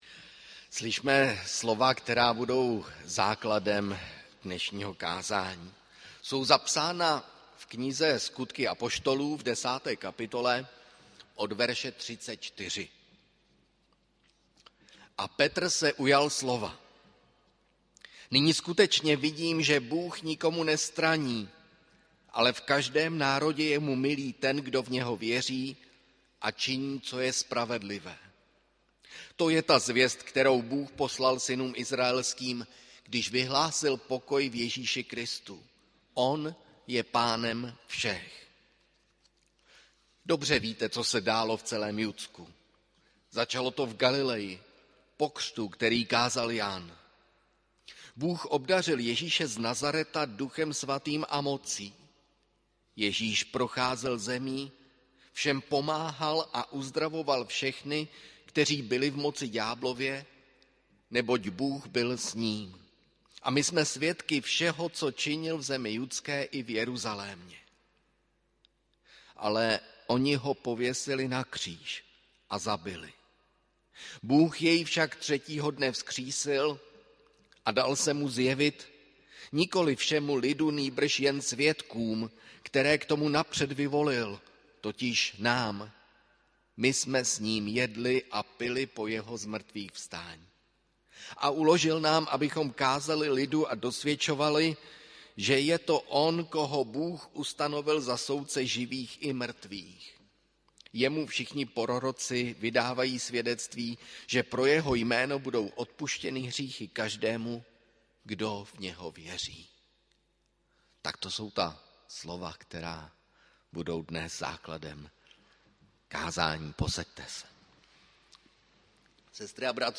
audio kázání
Boží hod velikonoční 9. dubna 2023 AD